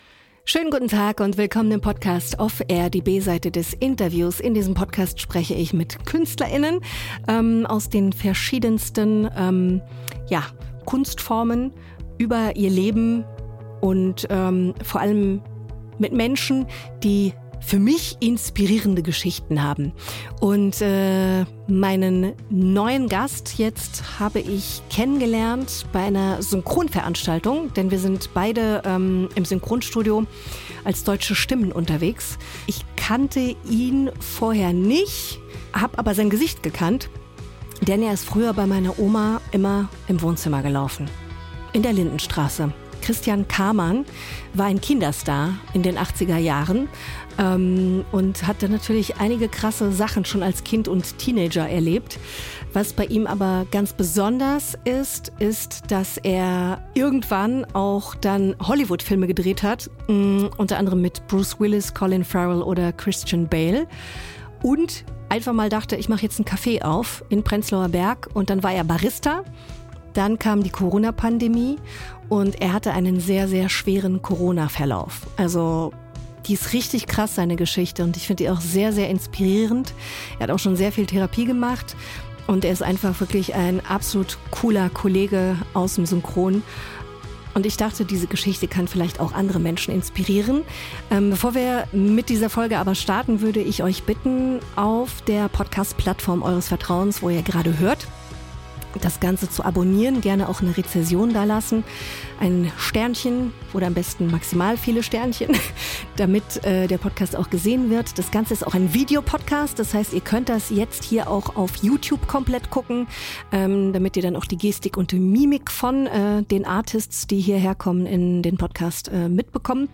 Christian spricht offen darüber, ab wann man eigentlich Alkoholiker ist, wie sich das schleichend entwickelt und wie er seinen Entzug geschafft hat. Später erkrankt er selbst schwer: Corona führt zu 17 Tagen Koma, zwei Monaten Intensivstation und einem vollständigen körperlichen Neuanfang.